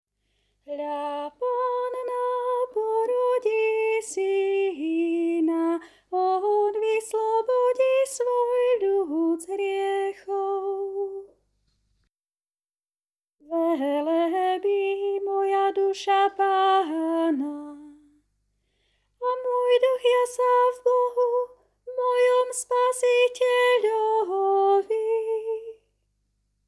00:00 00:00 MP3 na stiahnutie Hľa panna porodí syna (Soprán) Hľa panna porodí syna (Alt) Hľa panna porodí syna (Tenor) Hľa panna porodí syna (Bass)
Hla_panna_porodi_syna-Sopran.mp3